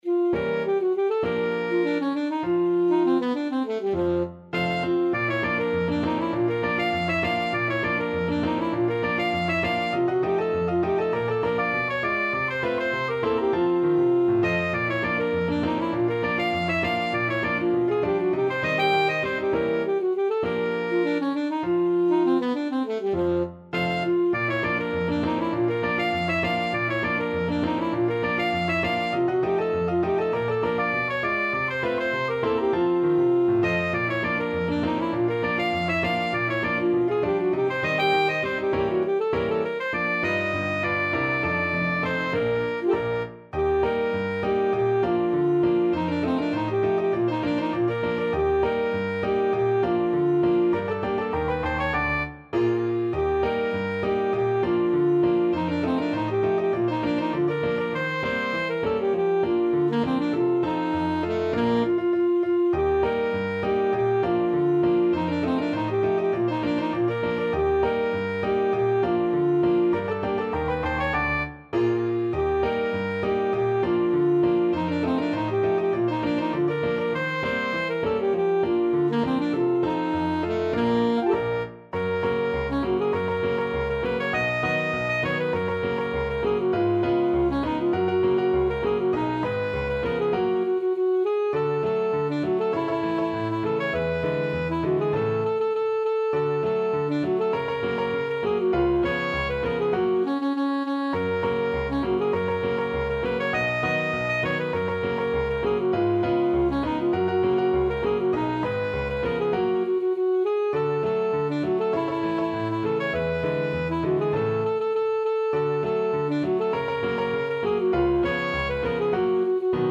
Alto Saxophone
2/4 (View more 2/4 Music)
Jazz (View more Jazz Saxophone Music)
Ragtime Music for Alto Sax